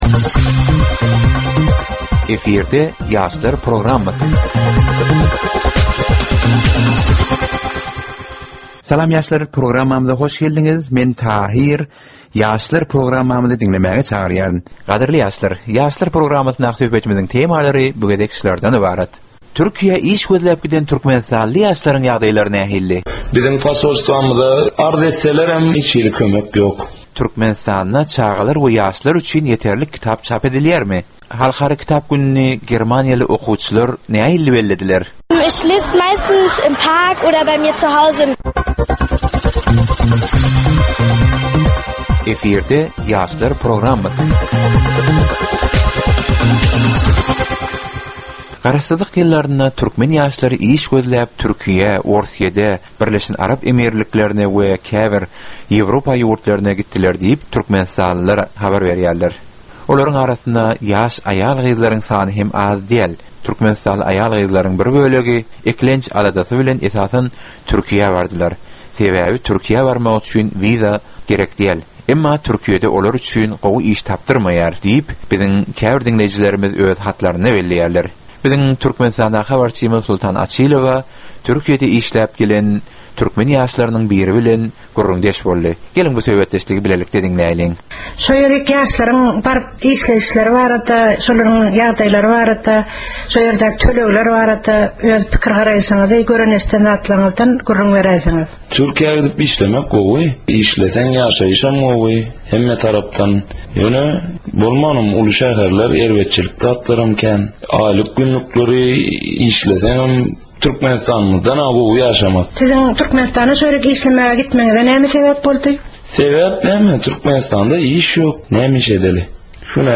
Gepleşigiň dowamynda aýdym-sazlar hem eşitdirilýär.